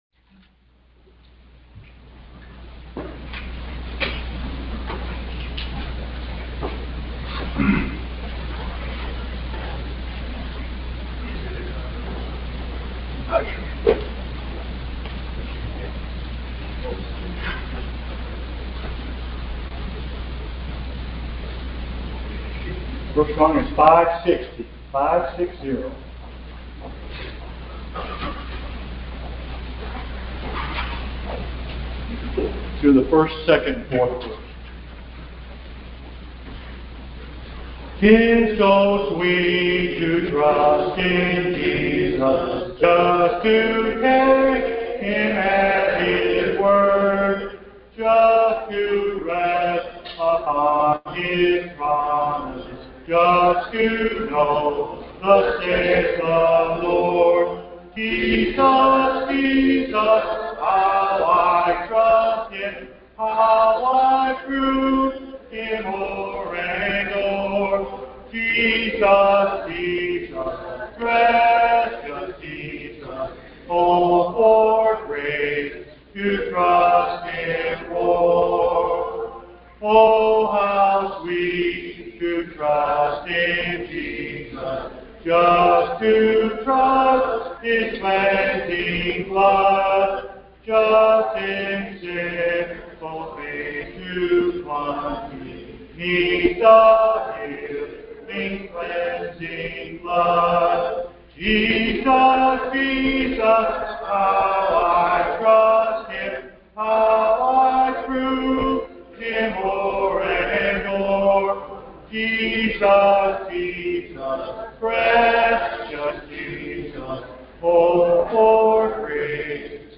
Sunday Service 12.25.22